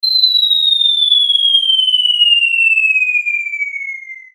SFX掉下来1音效下载
SFX音效